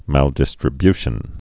(măldĭs-trə-byshən)